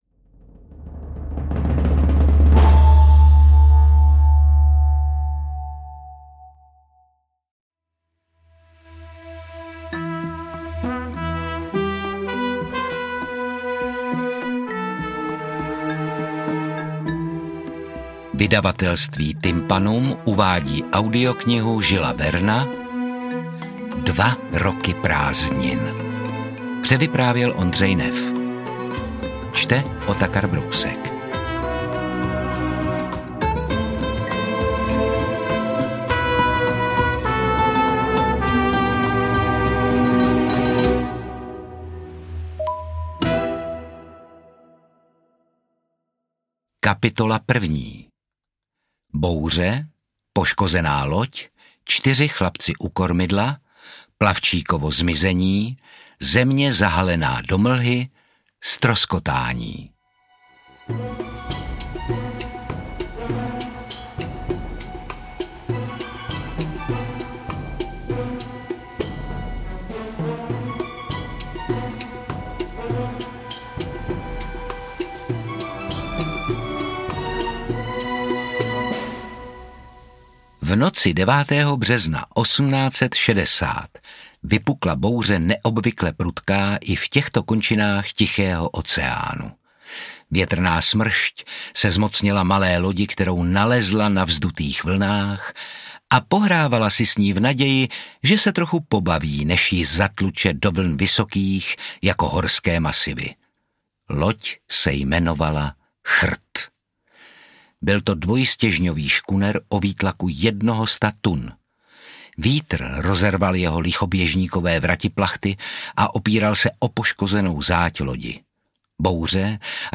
Interpret:  Otakar Brousek